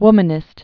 (wmən-ĭst)